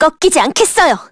Jane_L-Vox_Skill5_kr.wav